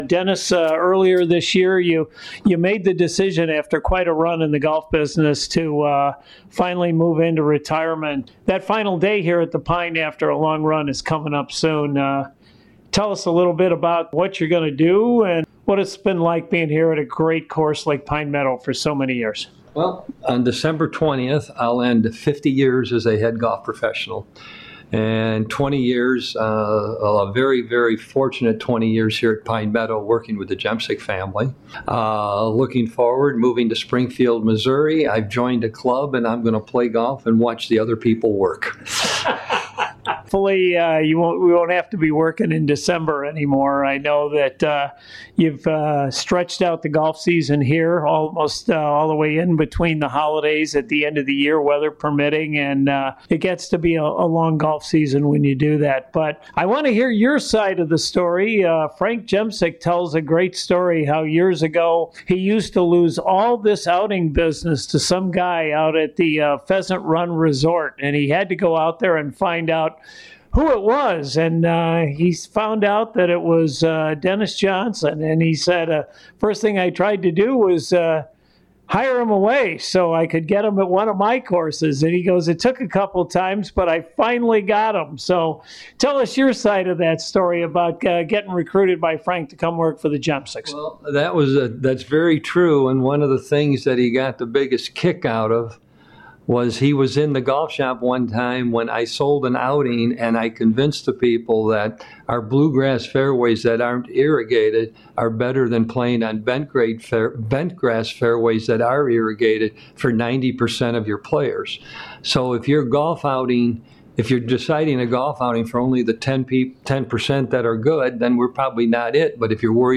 LIVE broadcasts from 9am to 10am on Saturdays on WNDZ AM-750